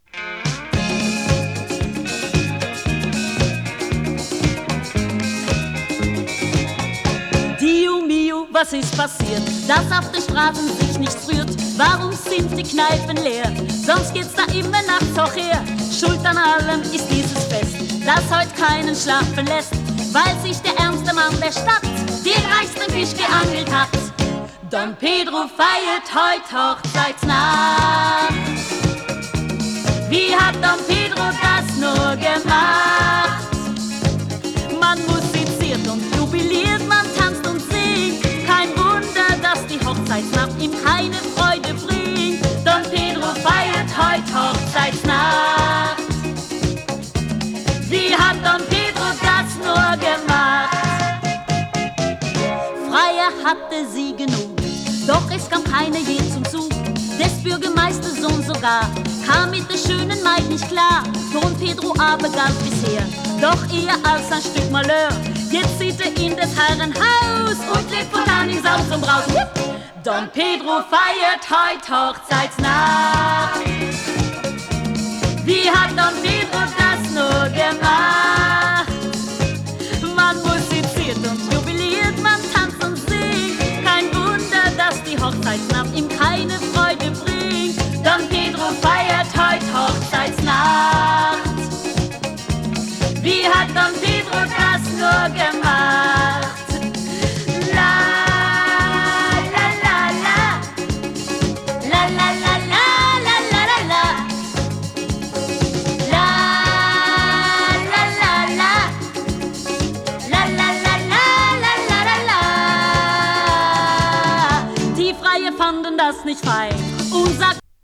ノルウェー出身のボーカリストによるドイツ語録音。